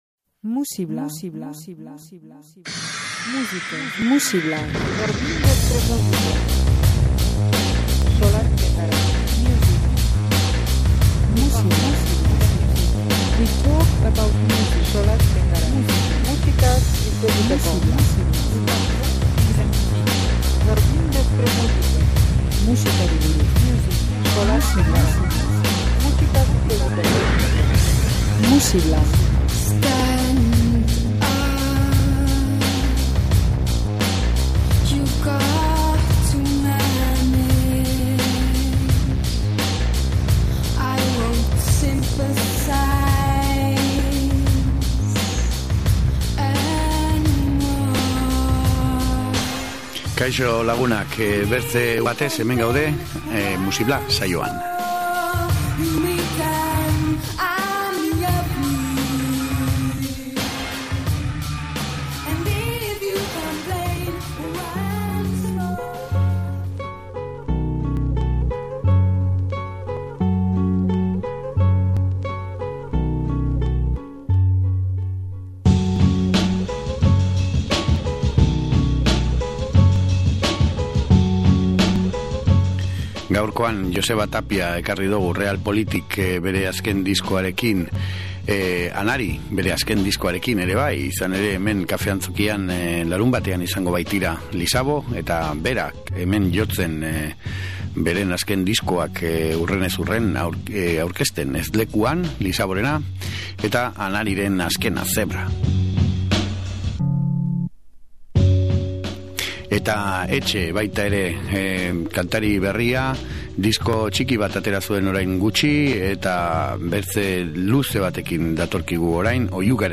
Bakarlariz jositako saioa gaurkoa!